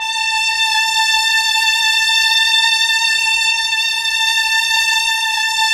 Index of /90_sSampleCDs/Roland LCDP09 Keys of the 60s and 70s 1/KEY_Chamberlin/STR_Chambrln Str